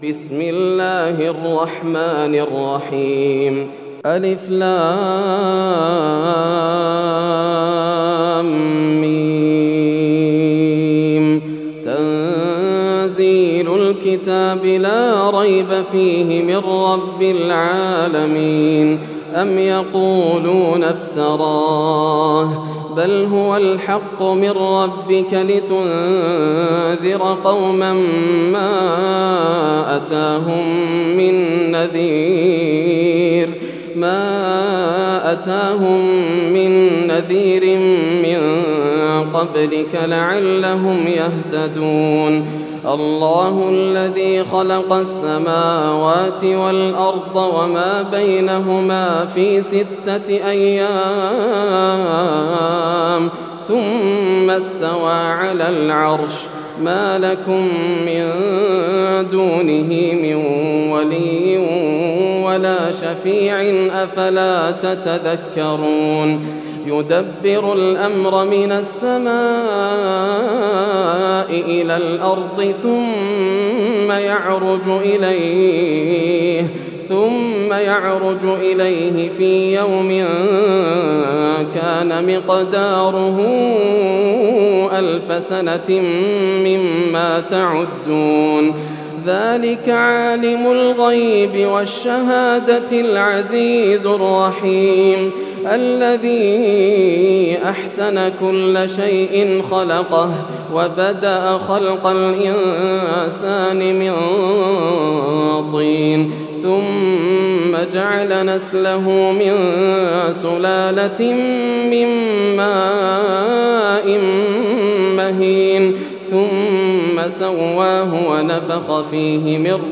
سورة السجدة > السور المكتملة > رمضان 1431هـ > التراويح - تلاوات ياسر الدوسري